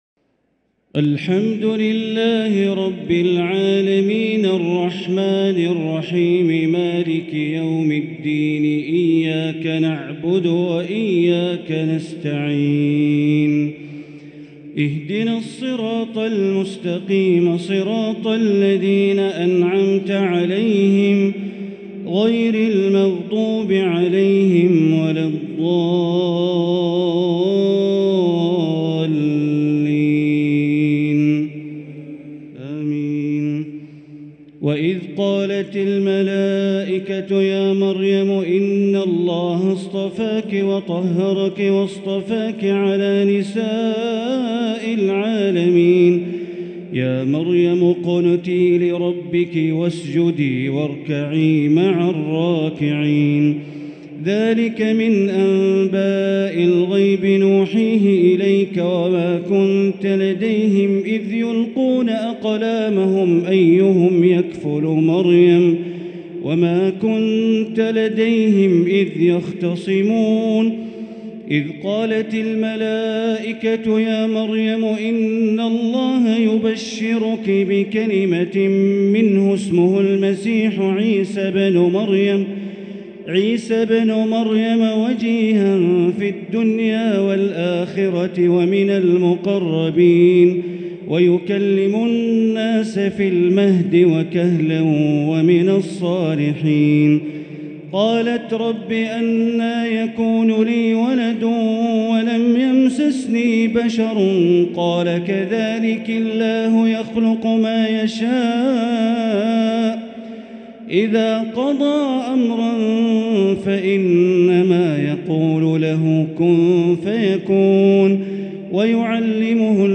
تراويح ليلة 4 رمضان 1444هـ من سورة آل عمران {42-92} Taraweeh 4st night Ramadan 1444H Surah Aal-i-Imraan > تراويح الحرم المكي عام 1444 🕋 > التراويح - تلاوات الحرمين